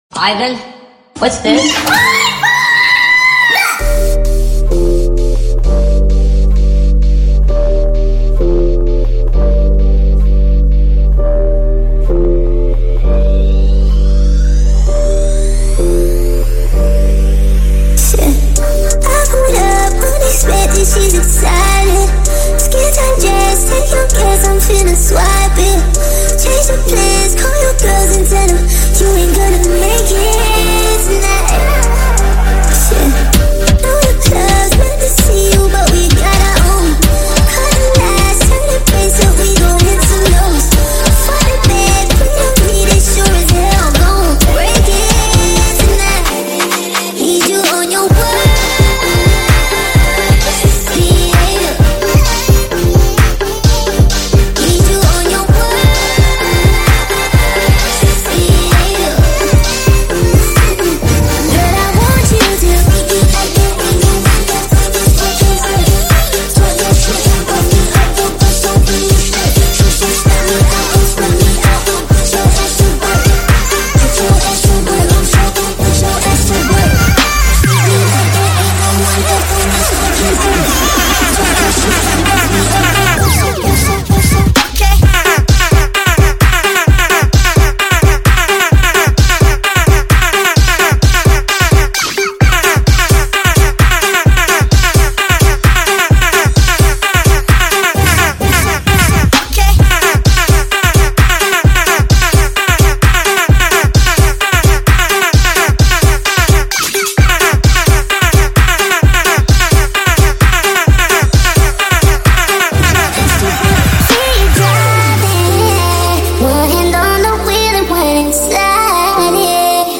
Remix | indobounce